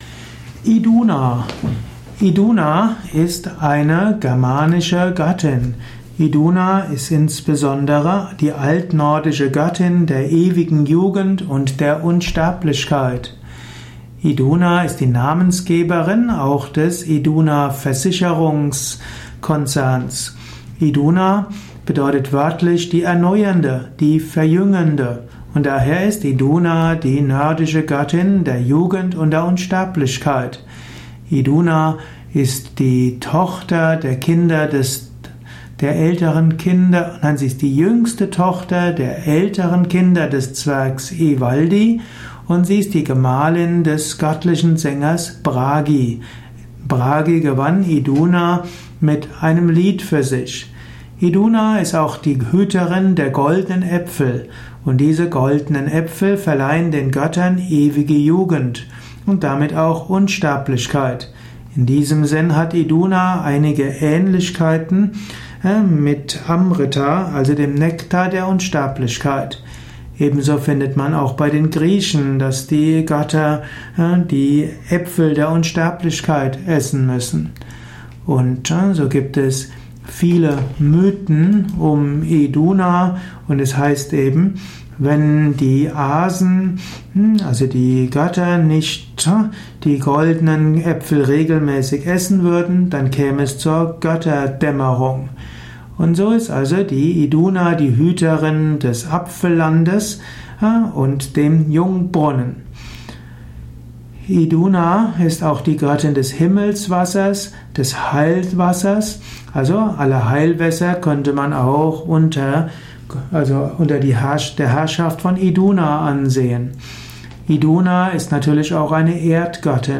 Ein Vortrag über Iduna, einer nordischen Göttin. Beschreibung der Signifikanz von Iduna in der nordischen Mythologie, im nordischen Götterhimmel.
Dies ist die Tonspur eines Videos, zu finden im Yoga Wiki.